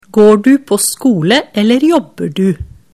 setningsdiktat_skolesystemet01.mp3